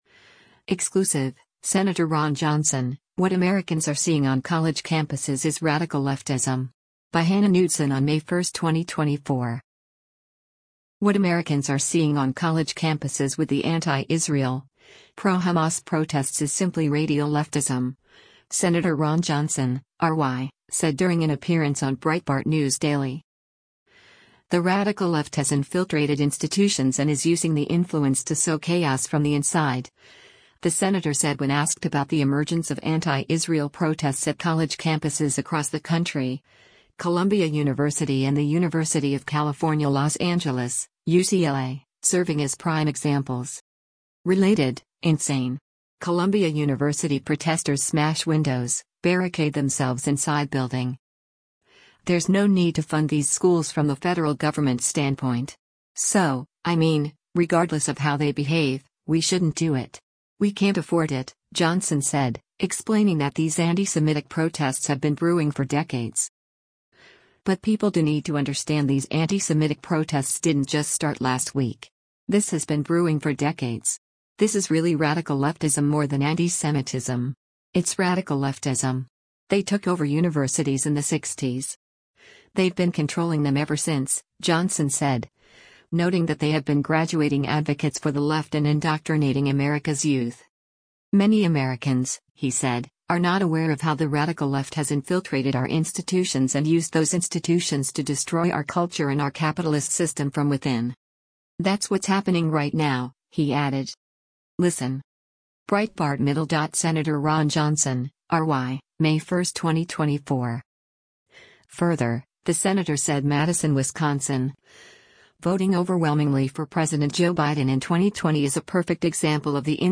What Americans are seeing on college campuses with the anti-Israel, pro-Hamas protests is simply “radial leftism,” Sen. Ron Johnson (R-WI) said during an appearance on Breitbart News Daily.